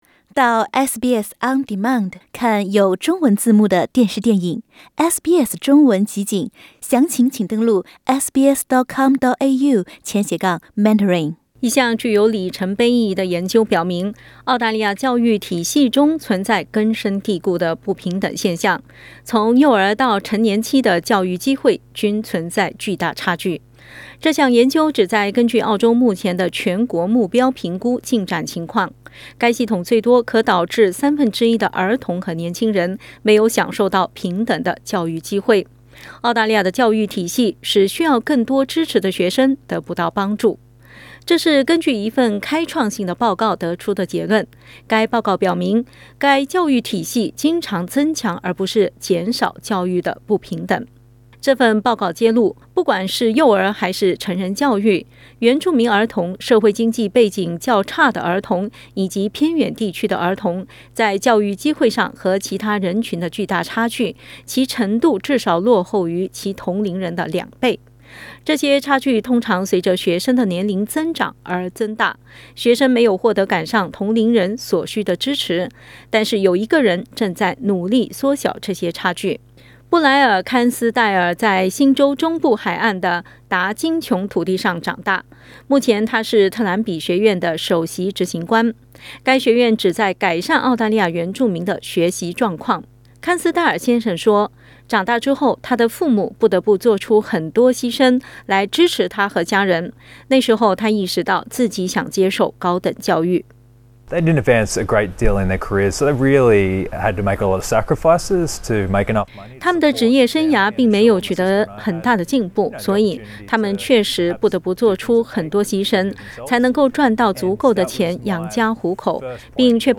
点击图片收听详细报道。